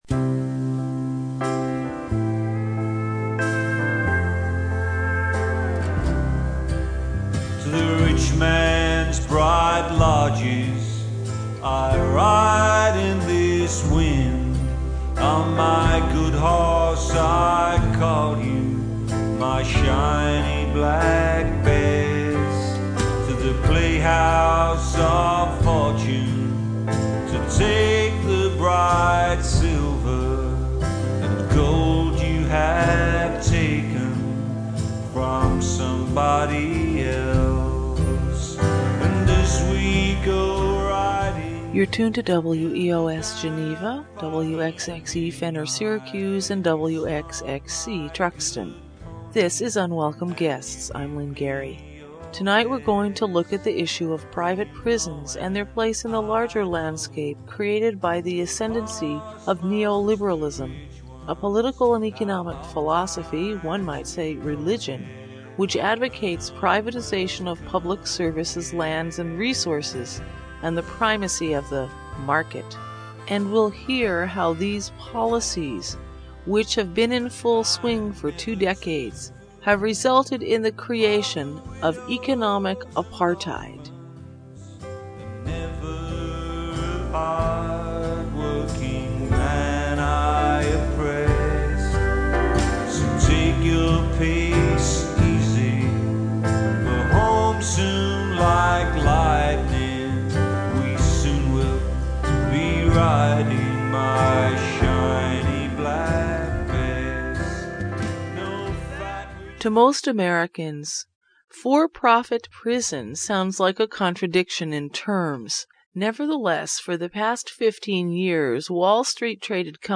This week we hear an Ithaca College forum on private prisons, water privatization